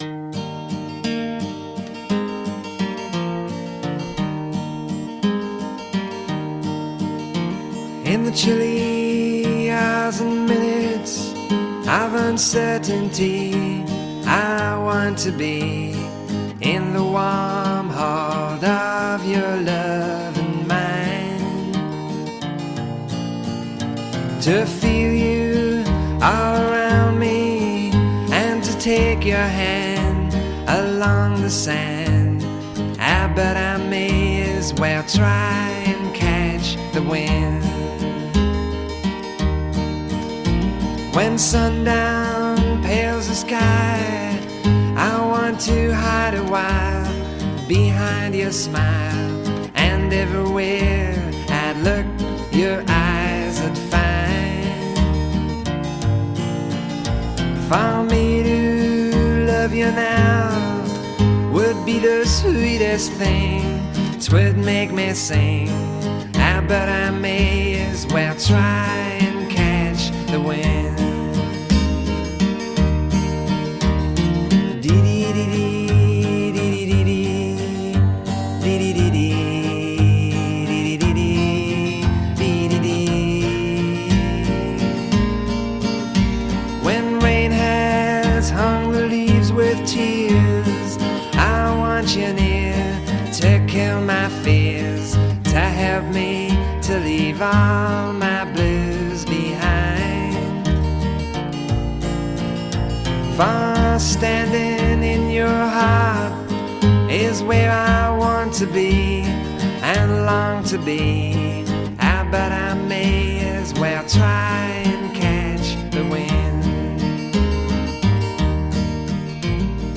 À la découverte d'un nom oublié du folk écossais...